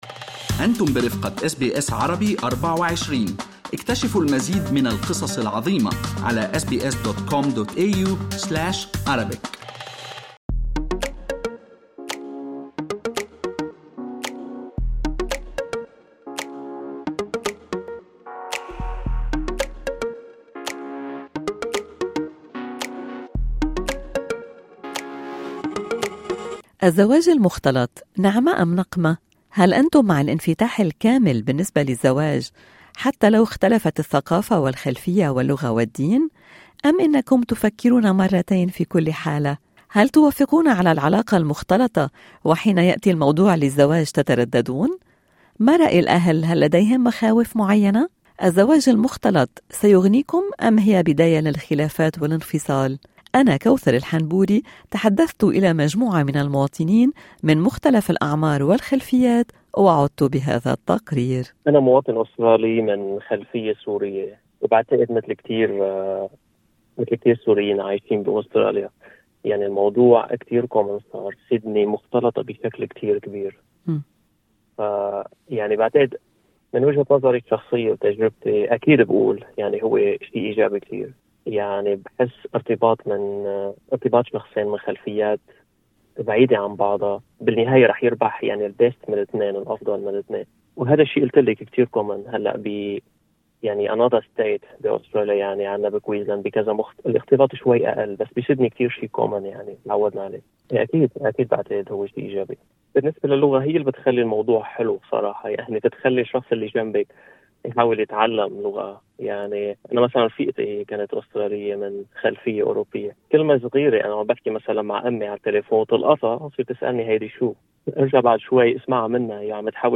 هل تعتبرون أن الزواج المختلط سيغنيكم أم هي بداية للخلافات والأنفصال؟ تحدثنا الى مجموعة من المواطنين الأستراليين من خلفيات عربية بأعمار مختلفة شباب أمهات وآباء أوضحوا لأس بي أس عربي وجهة نظرهم من خلال تجربتهم الخاصة: